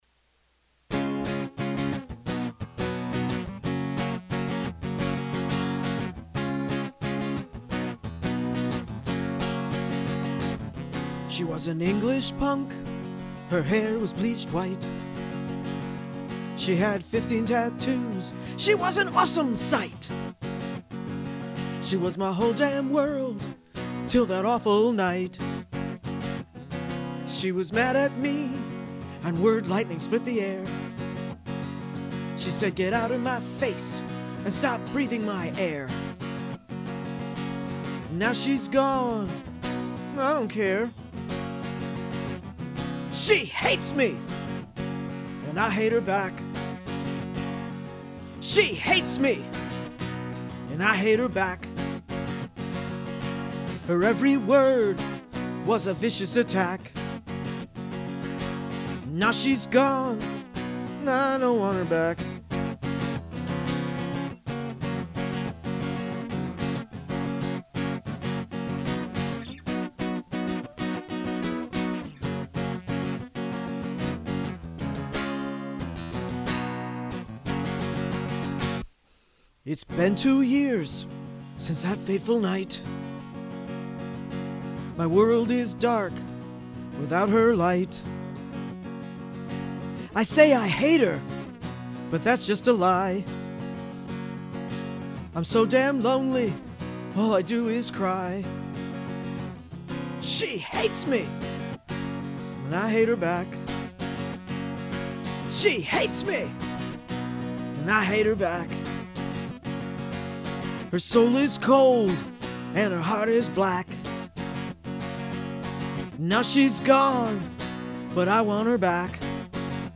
Politically Incorrect Modern Blues